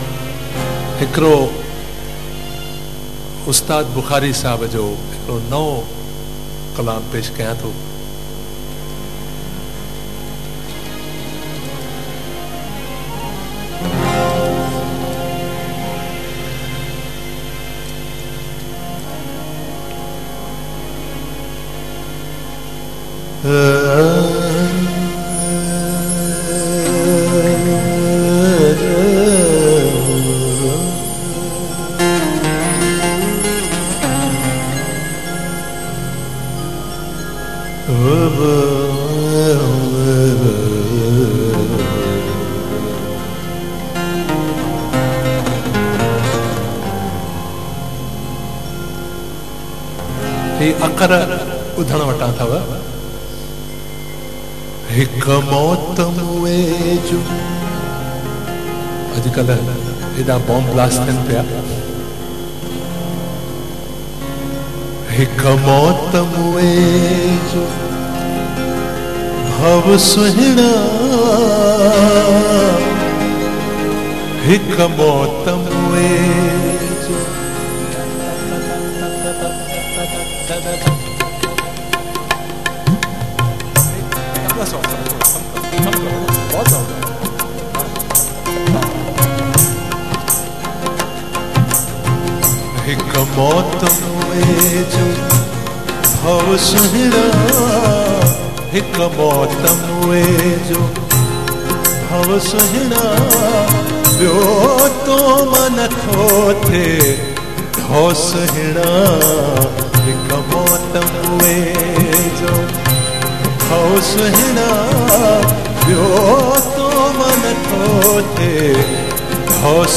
Performance at Town Hall, Sindhunagar 3 Format: LIVE
Live Performance